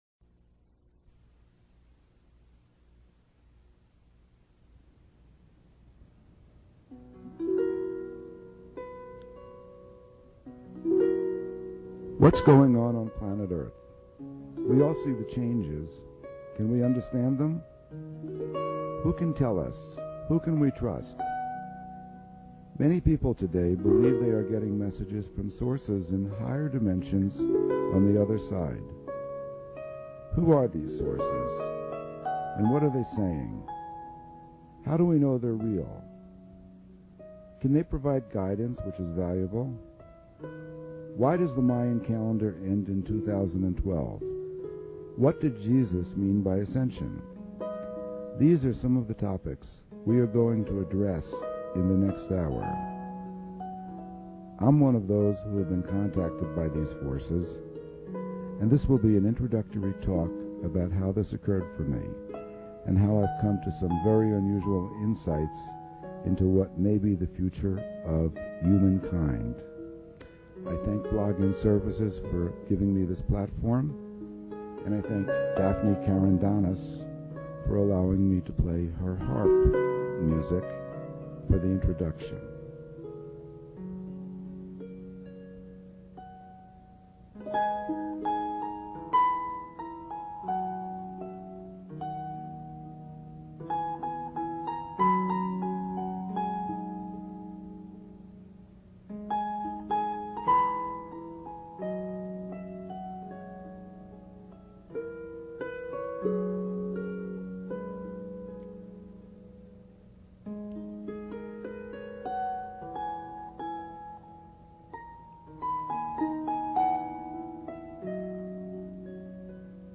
Wildcard Fridays with various Hosts on BBS Radio!